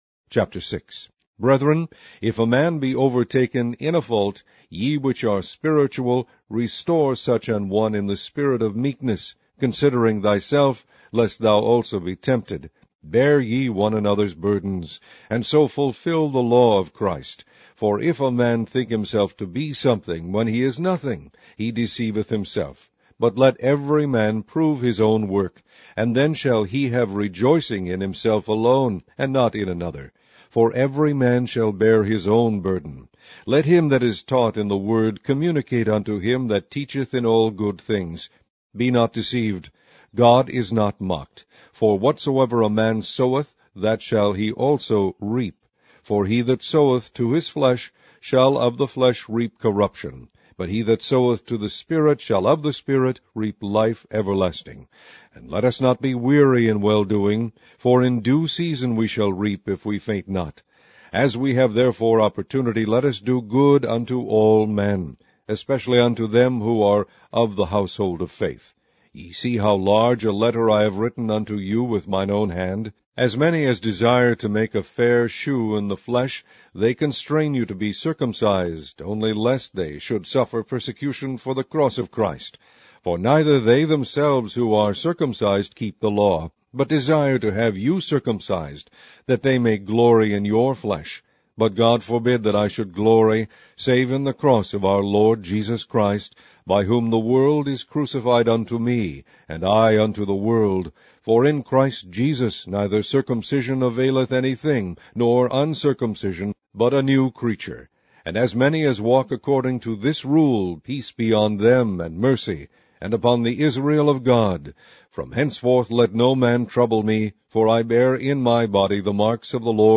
Online Audio Bible - King James Version - Galatians